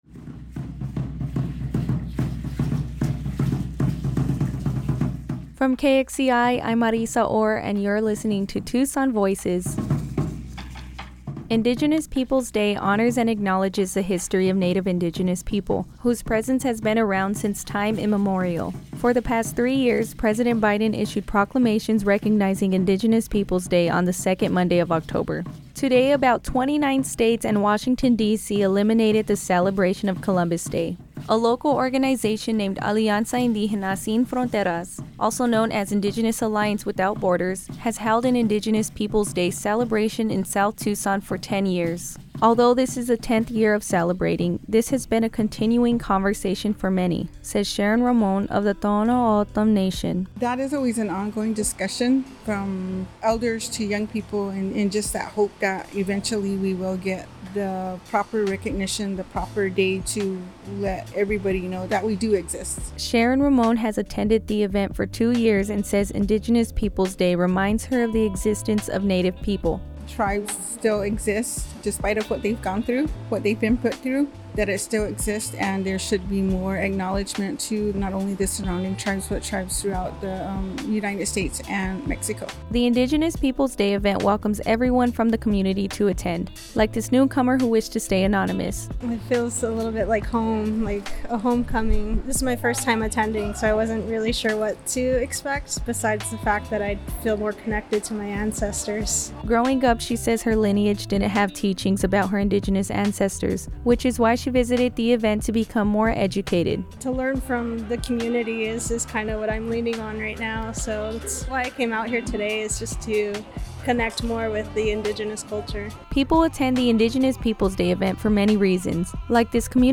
The sounds heard in this story are from the Calpolli Teoxicalli Dance Group.
The background music is titled Remember 04 by The Halluci Nation feat. Re.verse, Chippewa Travellers.